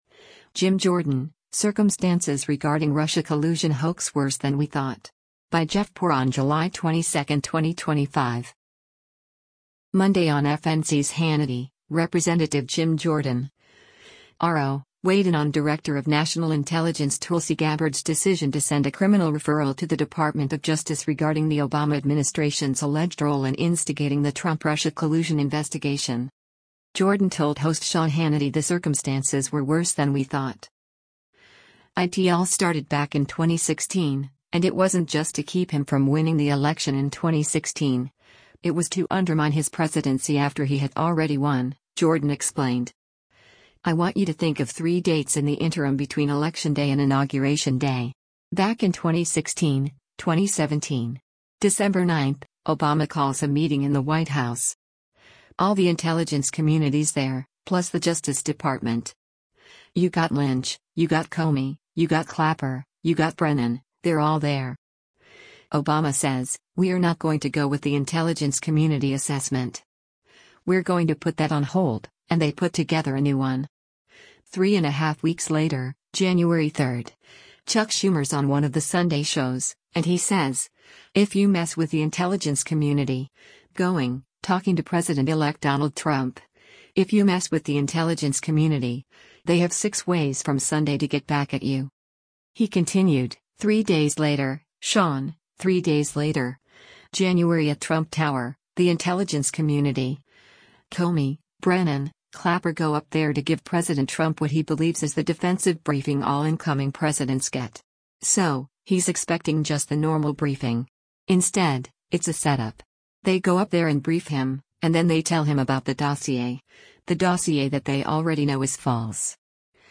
Monday on FNC’s “Hannity,” Rep. Jim Jordan (R-OH) weighed in on Director of National Intelligence Tulsi Gabbard’s decision to send a criminal referral to the Department of Justice regarding the Obama administration’s alleged role in instigating the Trump-Russia collusion investigation.
Jordan told host Sean Hannity the circumstances were “worse than we thought.”